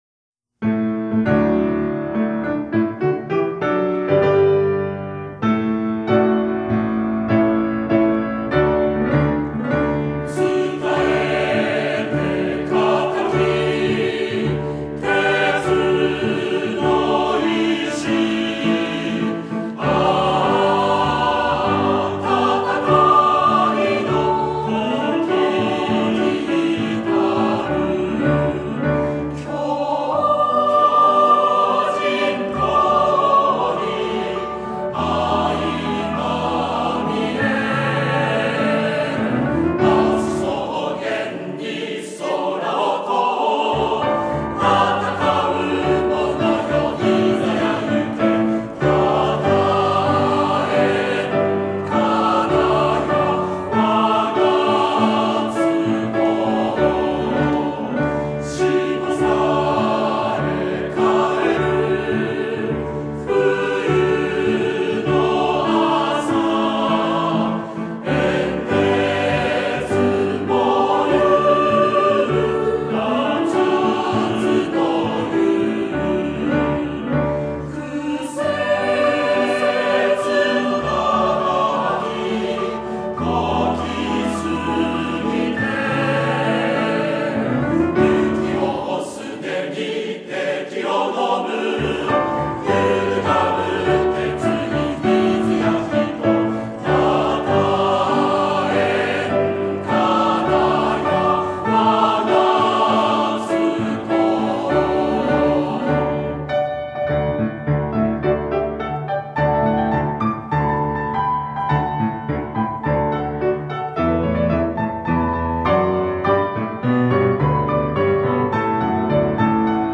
校歌（フルコーラス 演奏：津高等学校音楽部OB／MP3：850KB／2分54秒）